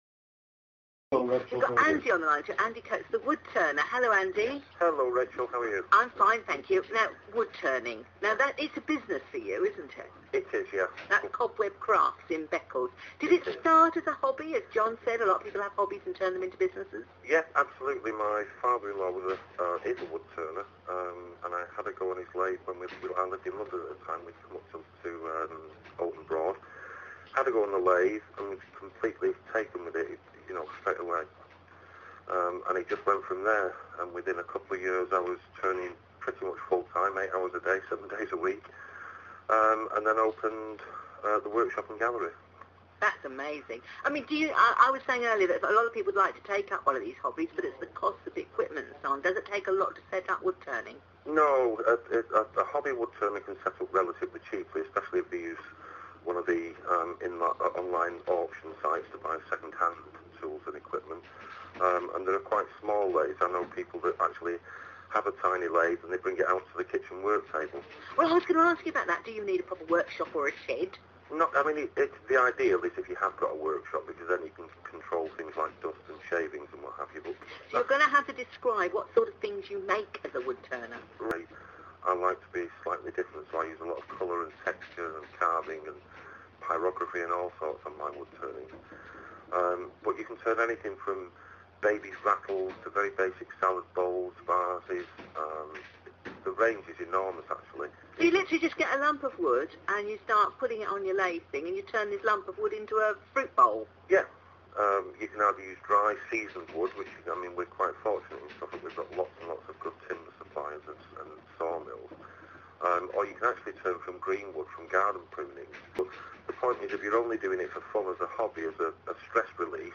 edited version of radio suffolk interview on 28th december 2011